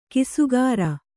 ♪ kisugāra